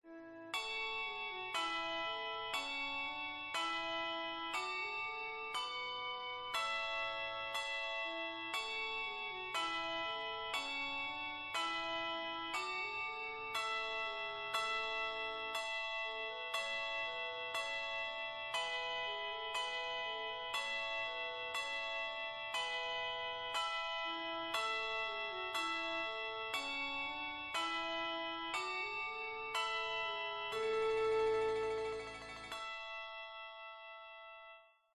Simple chordal descant
Handbells/Handchimes 2 octaves.
Descant Chordal Descant.